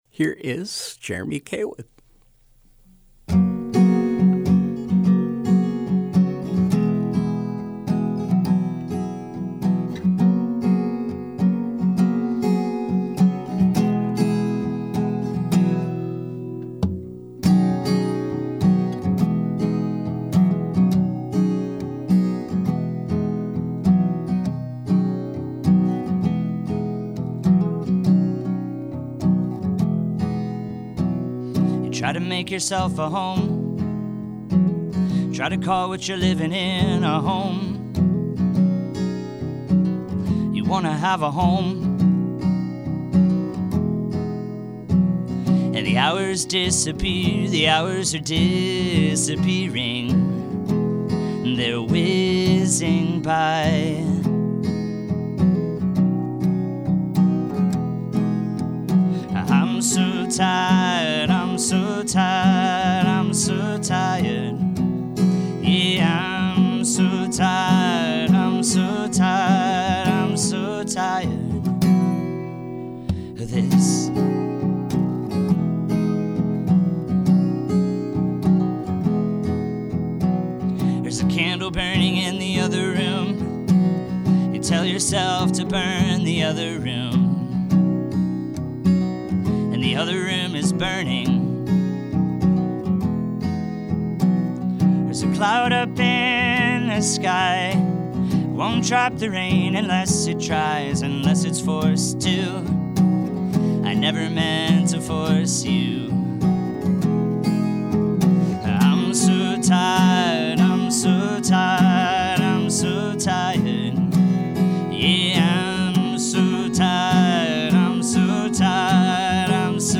Acoustic singer-songwriter